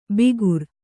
♪ bigur